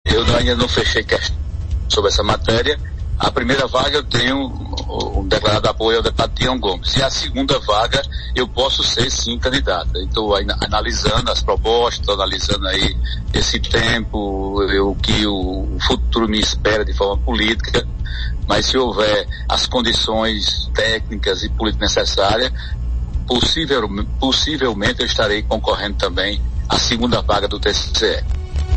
O presidente da Assembleia Legislativa da Paraíba (ALPB), Adriano Galdino (Republicanos), em entrevista nesta quinta-feira (09), endossou a decisão da Comissão de Constituição Redação e Justiça da Casa (CCJ) que aumentou de 65 para 70 anos a idade mínima para indicação de vaga de conselheiro do Tribunal de Contas do Estado (TCE-PB) e aproveitou para reiterar seu apoio à indicação do deputado estadual Tião Gomes, do PSB, para a primeira vaga que surgir.
As declarações repercutiram na Arapuan FM.